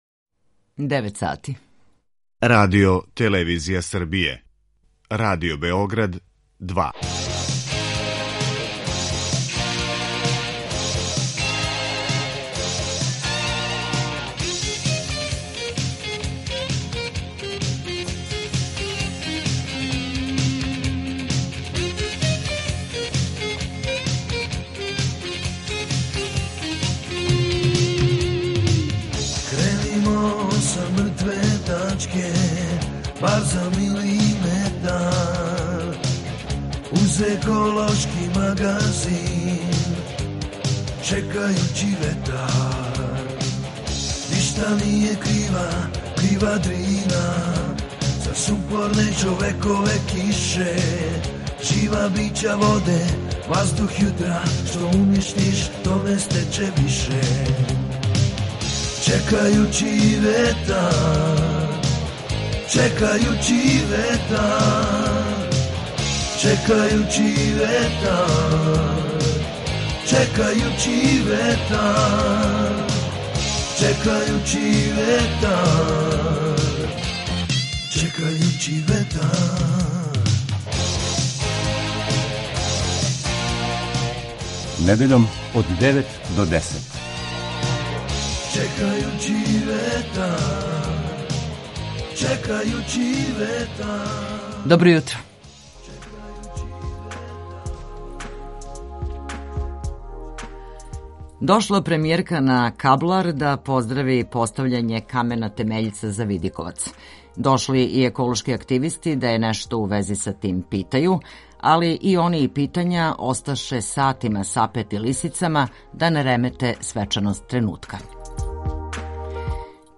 Фото: Радио Београд 2 ПРЕСЛУШАЈ: ОВДЕ Чекајући ветар - еколошки магазин Радио Београда 2 који се бави односом човека и животне средине, човека и природе.